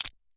Soda » soda can crunch 03
描述：我手里拿着一罐空罐子。 用Tascam DR40录制。
标签： 铝即可 嘎吱作响 苏打 罐头 饮料 苏打罐 挤压 金属 流行 粉碎 金属 饮料 食品 破碎 易拉罐 紧缩 嘎吱嘎吱
声道立体声